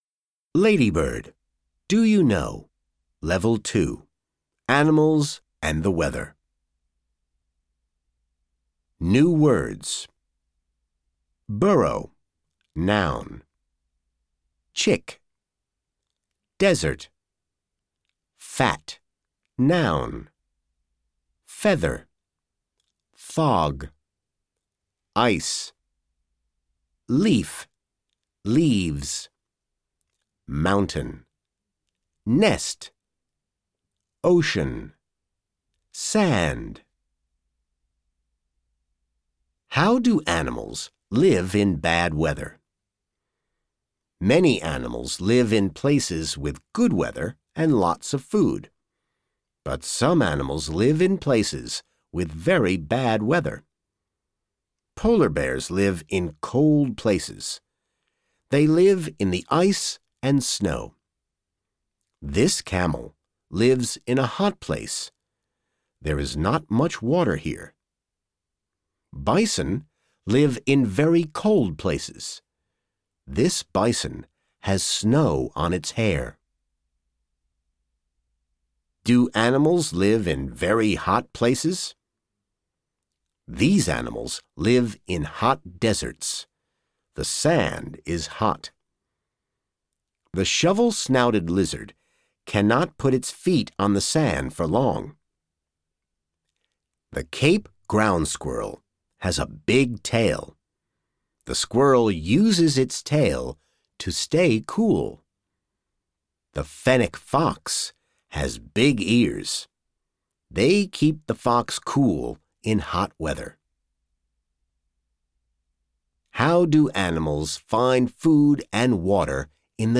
Audio US